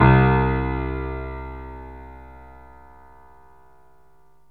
PIANO 0012.wav